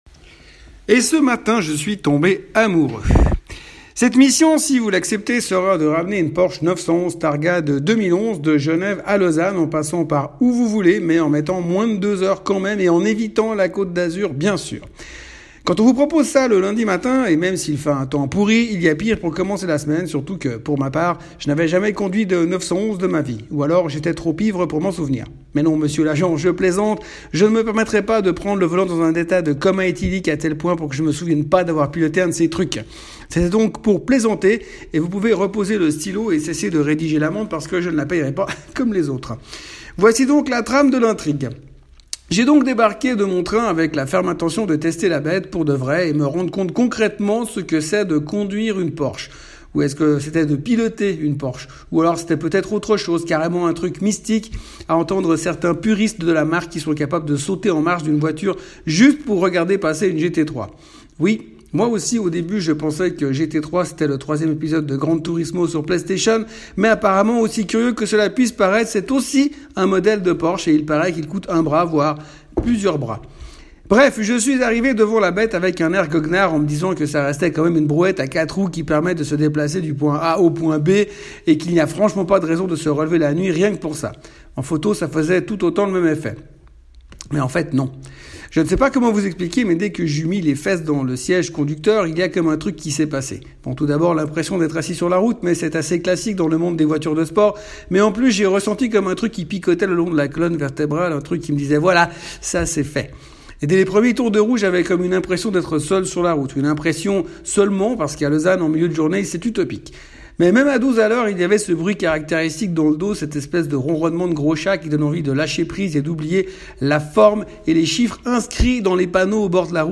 L’Audio avec les échappements ouverts.
porsche-997.mp3